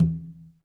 Conga-HitN_v1_rr2_Sum.wav